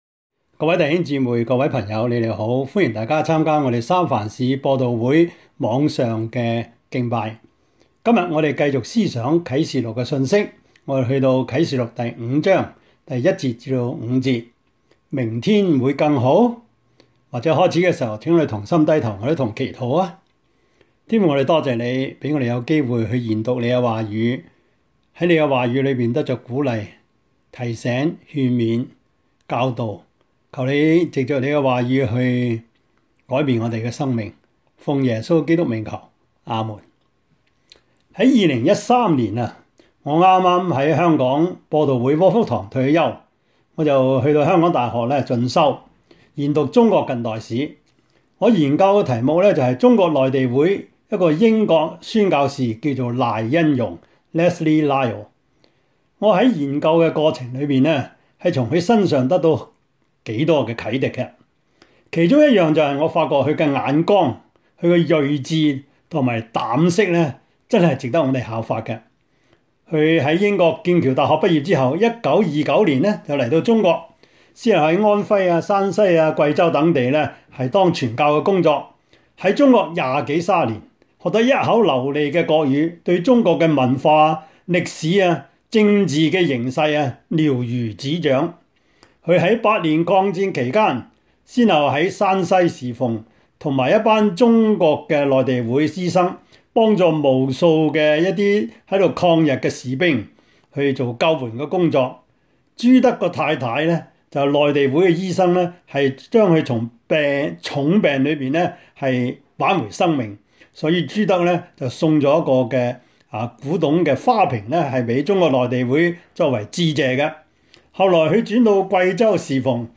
Topics: 主日證道 « 行奇事的神 現今的時機 »